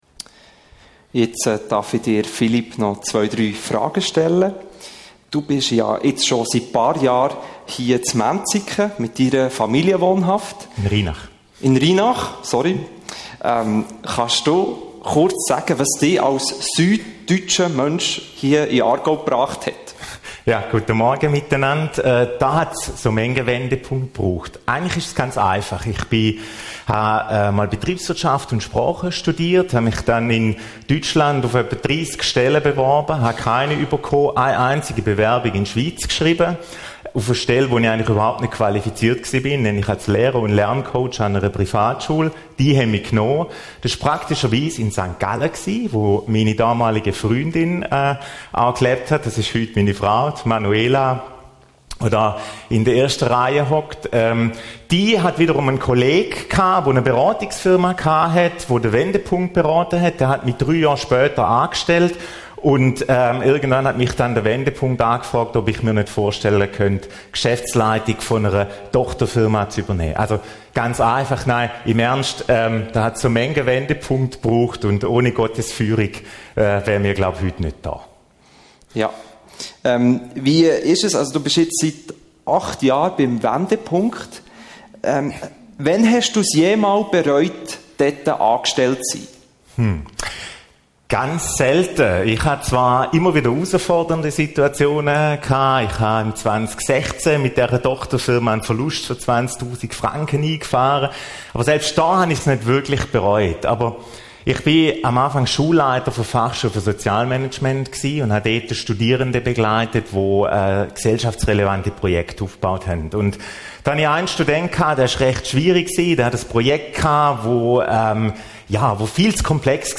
190623_predigt.mp3